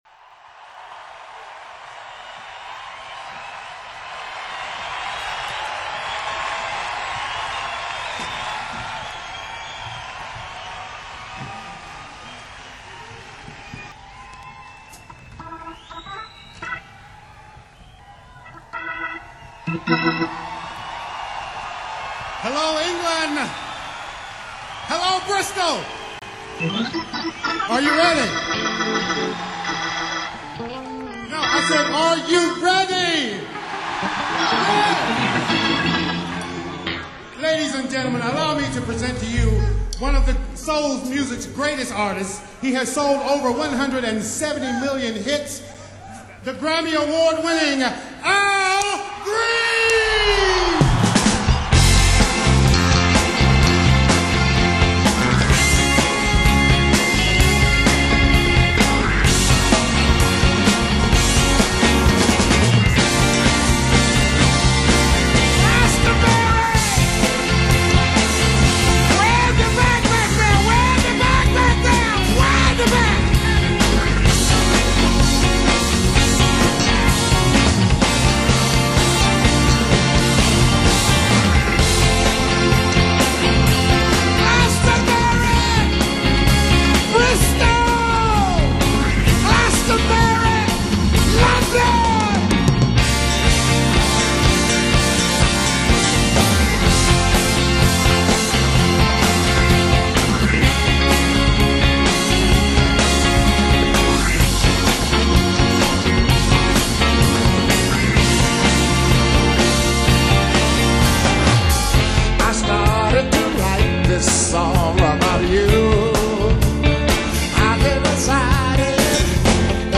live in concert at the Glastonbury Festival in 1999.